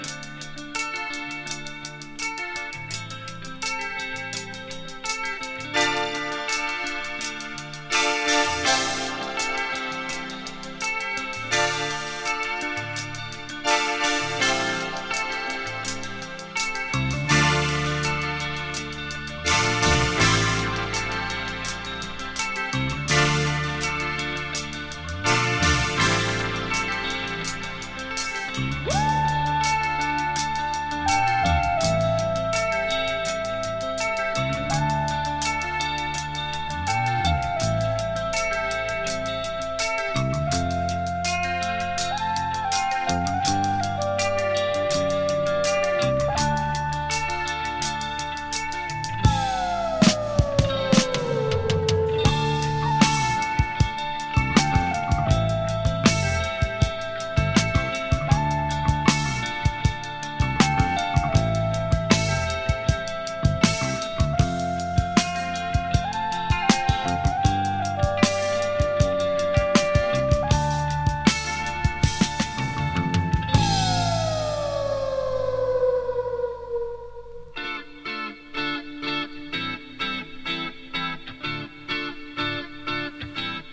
牧歌的なシンセが耳から離れない抒情サウンド